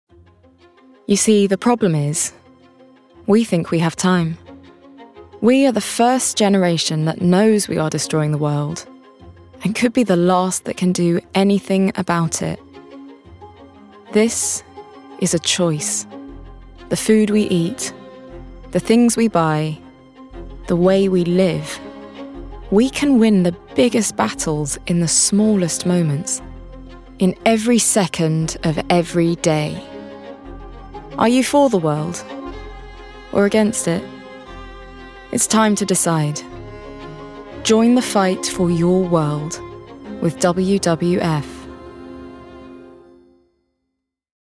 WWF - Grounded, Serious, Informative